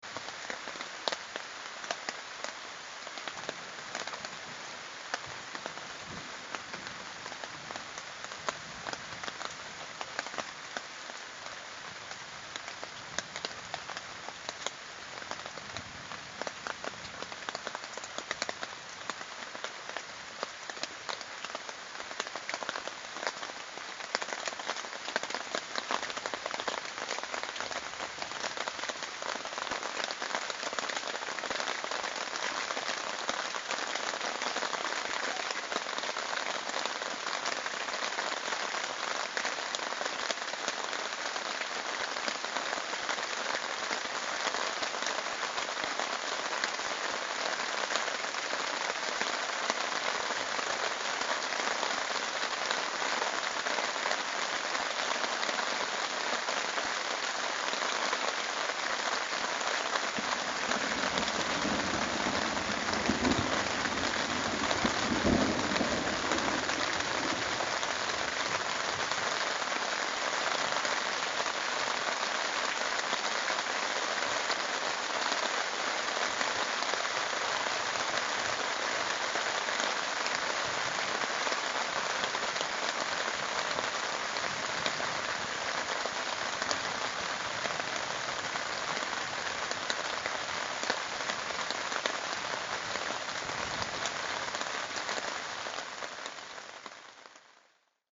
Once we finally got it all set up, the sprinkling became a thunderstorm!
On the plus side, a good rain at night makes people turn in early and makes a very pleasant sound to fall asleep with.
rain.mp3